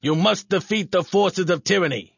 角色语音